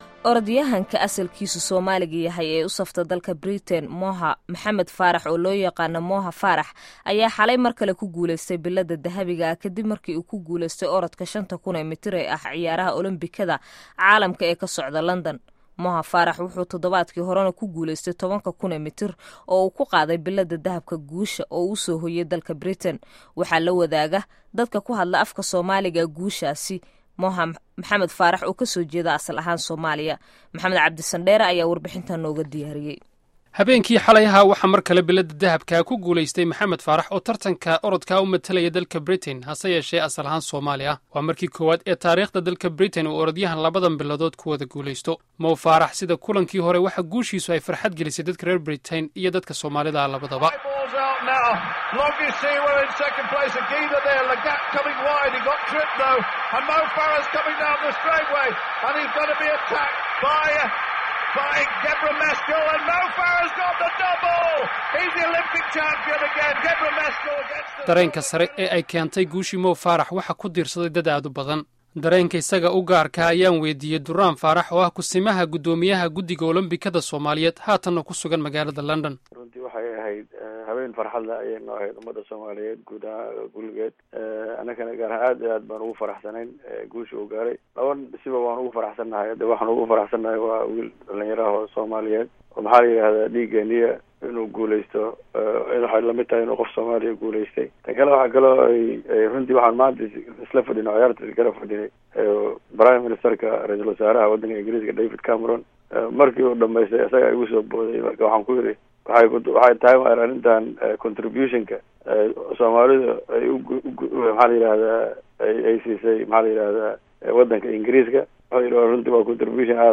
Warbixin: Guusha Mo Farah